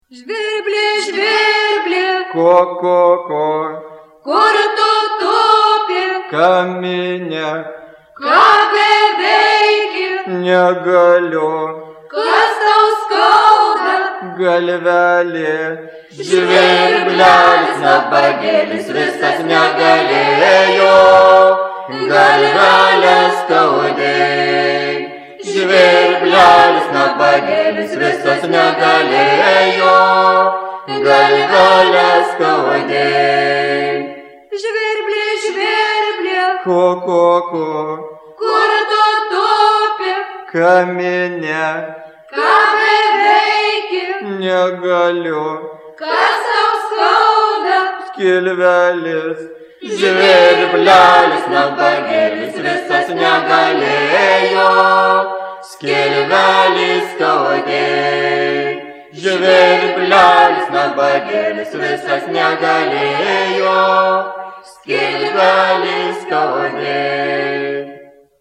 FOLKLORE> DANCES> Games
It is a circle and a game containing the elements of imitation and improvisation. The music consists of two parts. The melody of the first part consists of two bars, it is repeated four times, second part consists of twelve bars.